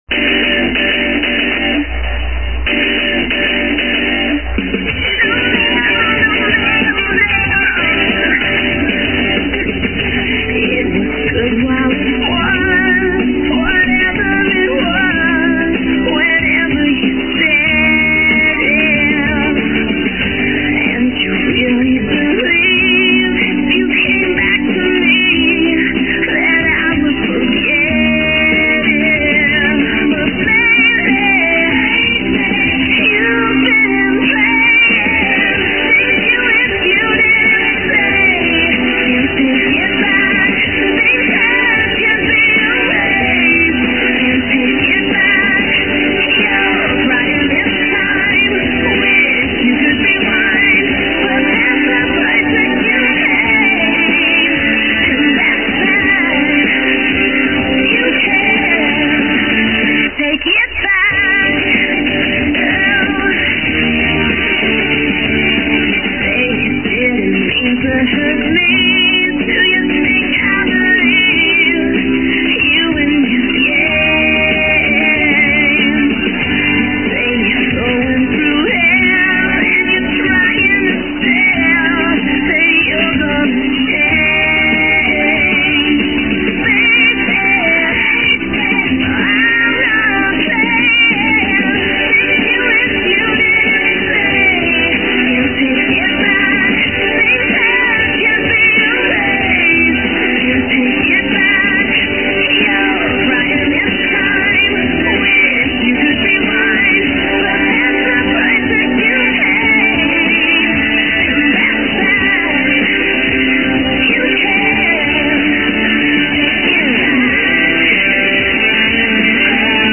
It's just cool 'cause it's a really rockin' song.